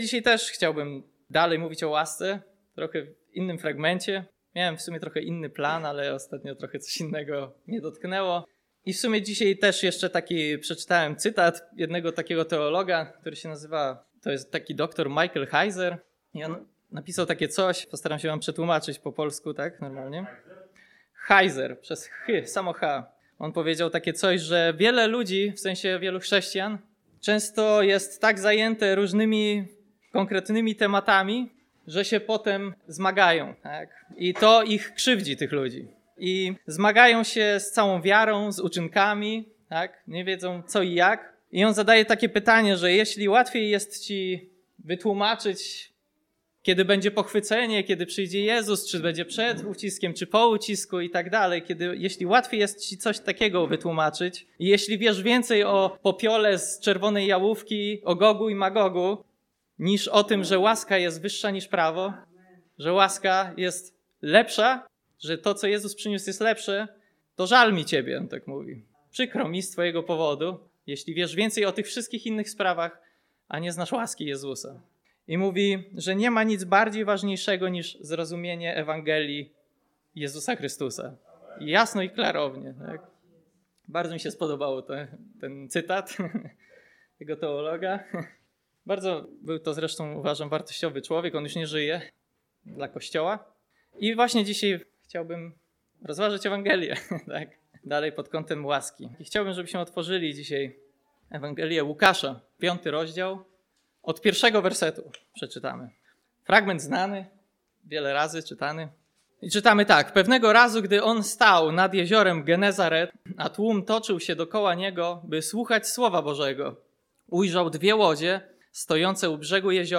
Posłuchaj kazań wygłoszonych w Zborze Słowo Życia w Olsztynie.